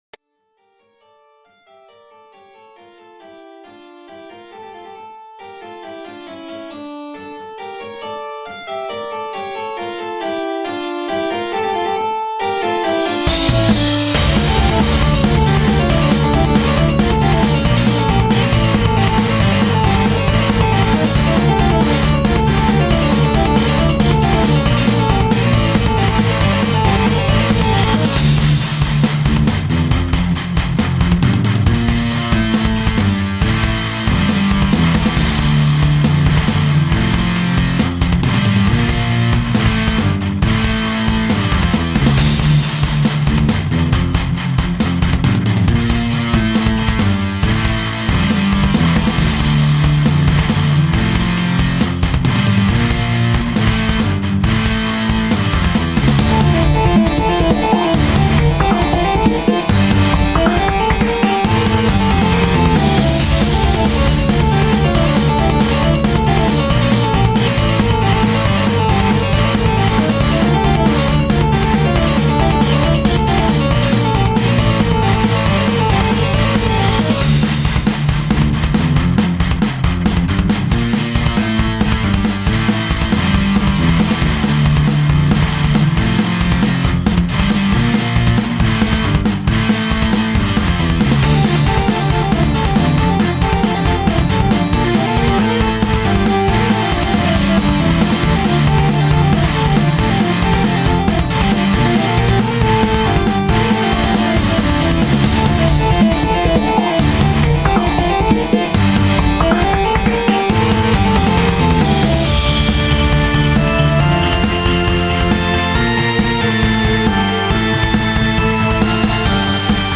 I also threw in some electronics to keep it kinda my own.